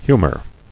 [hu·mour]